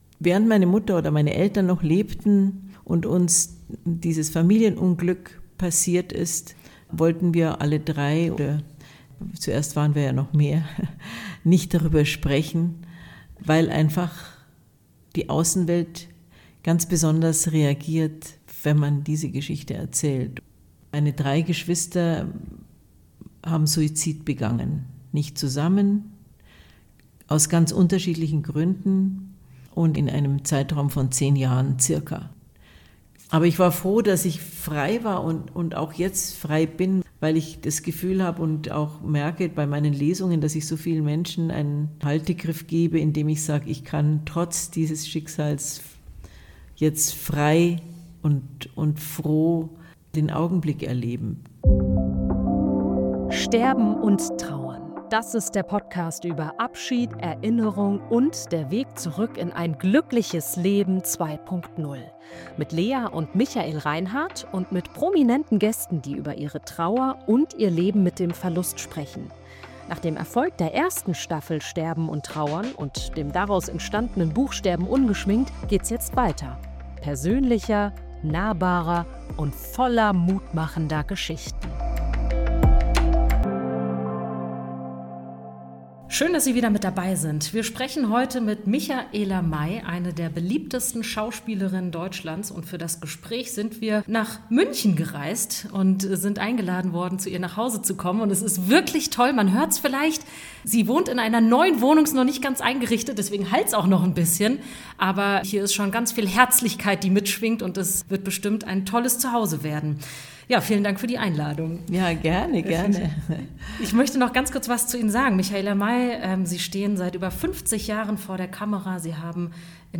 In ihrem Zuhause in München lässt sie uns an einer zutiefst persönlichen Lebensgeschichte teilhaben – fernab von Scheinwerferlicht und rotem Teppich.
Mit großer Klarheit und emotionaler Tiefe spricht sie über Schuldgefühle, gesellschaftliche Stigmatisierung, den Umgang mit Trauer – und darüber, wie sie dennoch ihre Lust aufs Leben nie verloren hat.